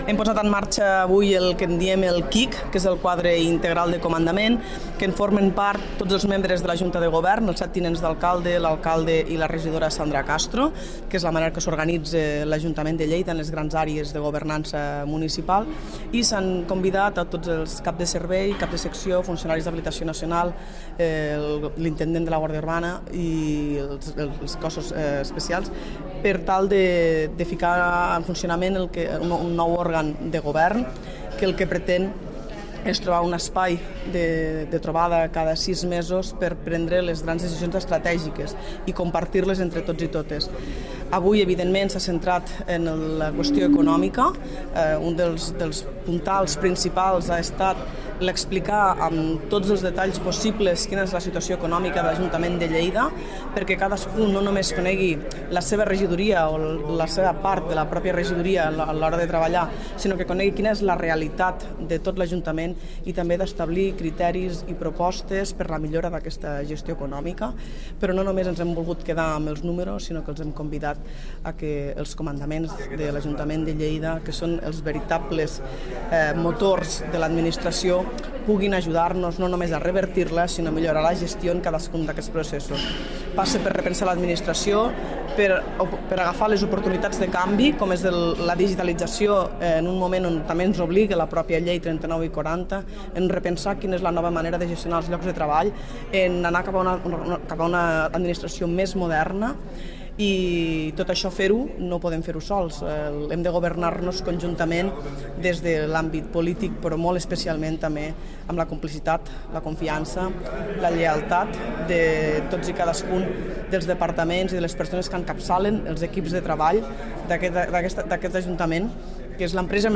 tall-de-veu-de-la-segona-tinent-dalcalde-jordina-freixanet-sobre-la-reunio-del-qic